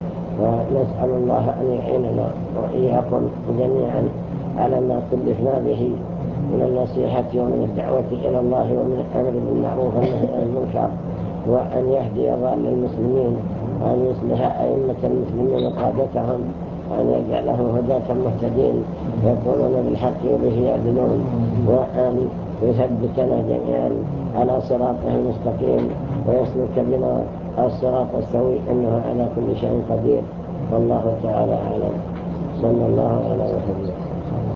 المكتبة الصوتية  تسجيلات - لقاءات  كلمة في الهيئة